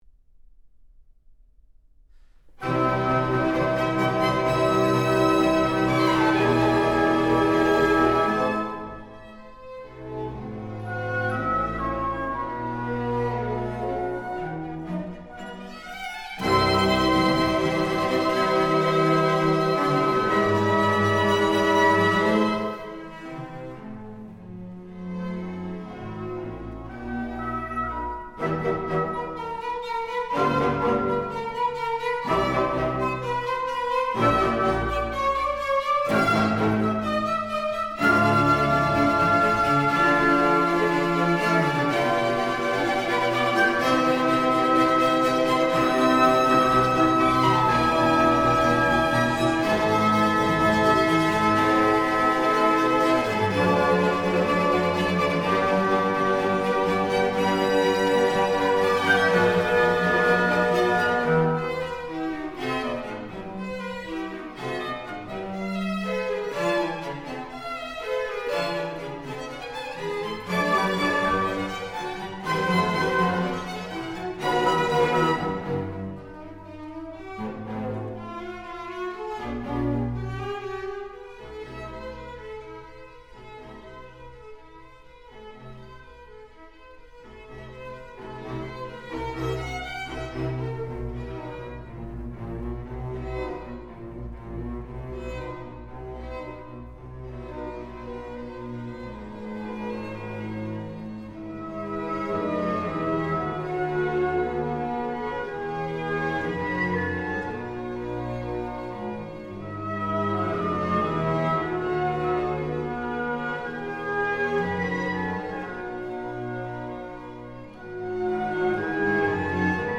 deux violoncelles et orchestre - 1 Allegro non troppo